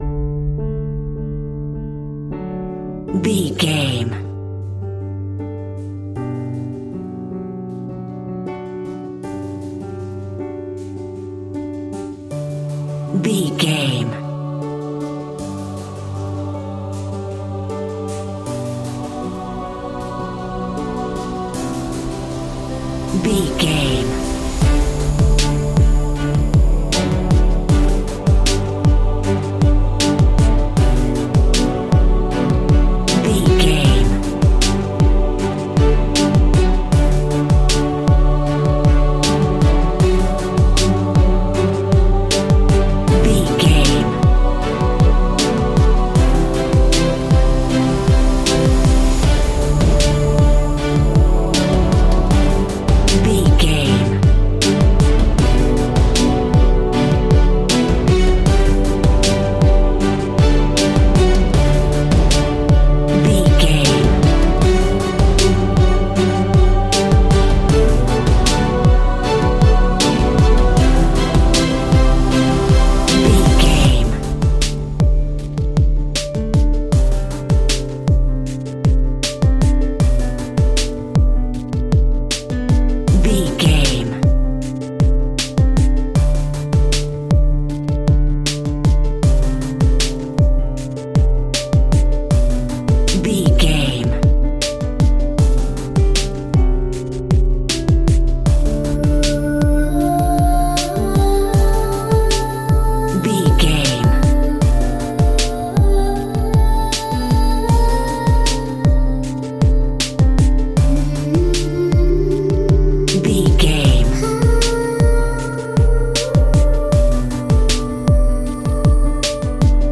Ionian/Major
D
strings
percussion
synthesiser
brass
violin
cello
double bass